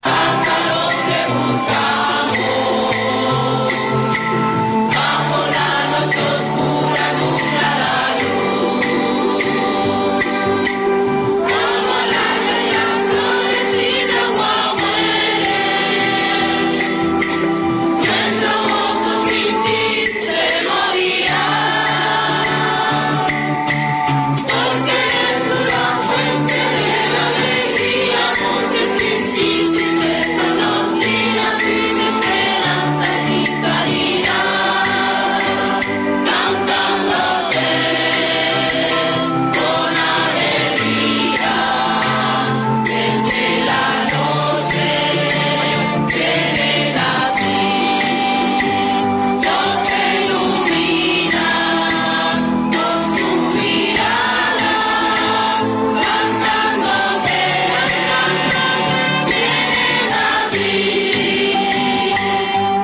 Real Audio of church singers.
A1-ChurchSingers.ra